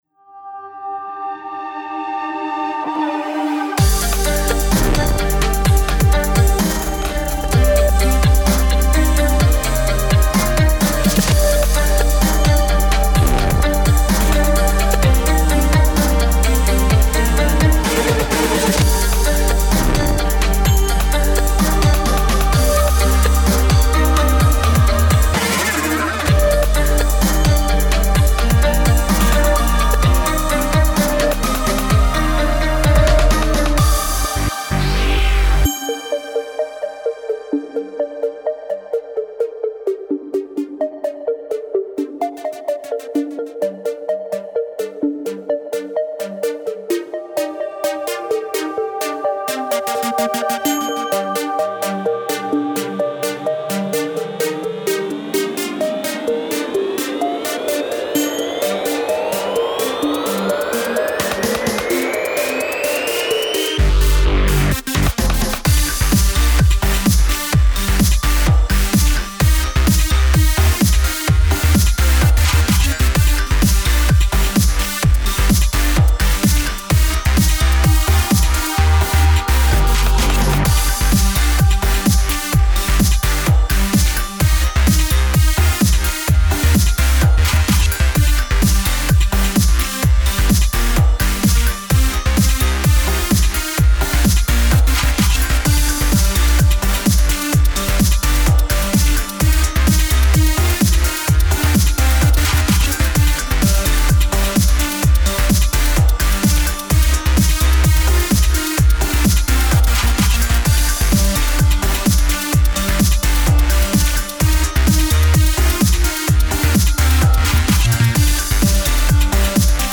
Genre: Progressive.